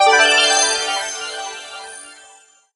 get_pickup_02.ogg